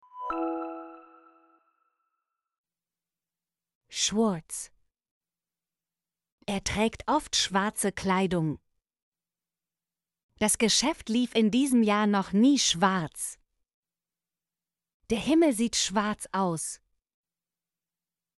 schwarz - Example Sentences & Pronunciation, German Frequency List